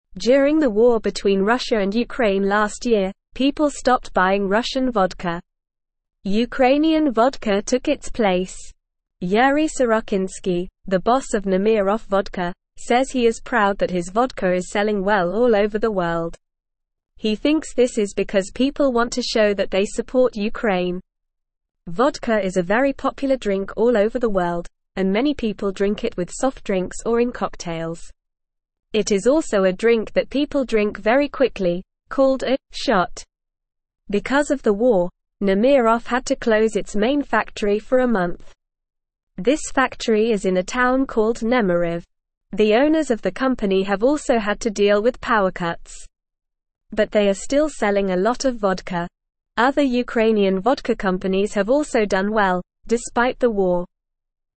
Normal
English-Newsroom-Beginner-NORMAL-Reading-Ukrainian-Vodka-Popular-During-War.mp3